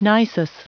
Prononciation du mot : nisus